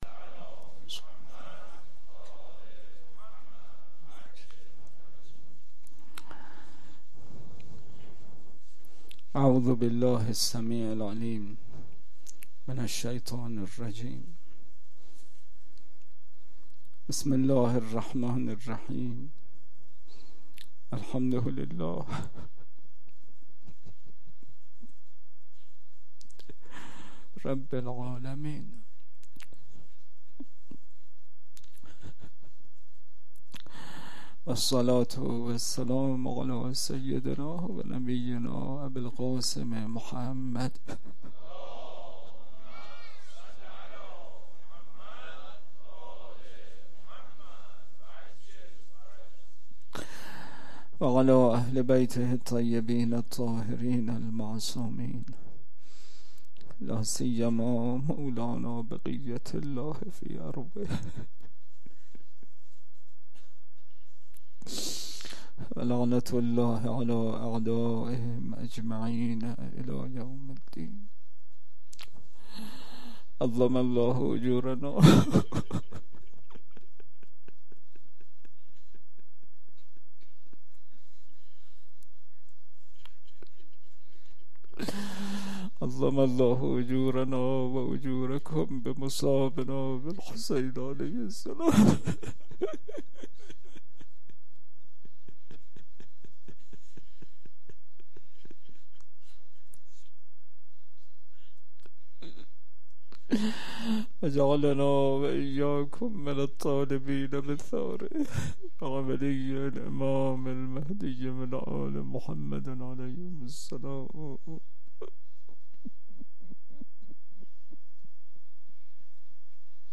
سخنرانی ها